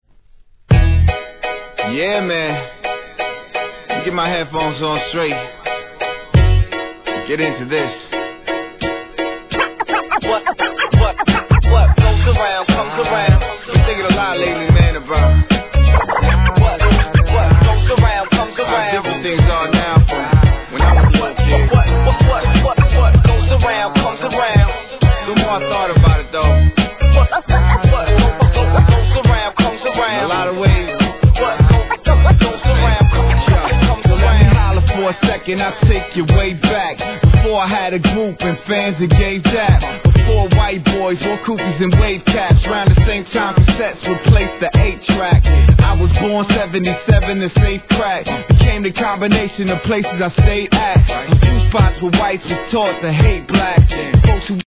HIP HOP/R&B
PIANOの効果的なA-1にはじまり、高完成度の美メロJAZZY HIP HOPナンバーが満載です！！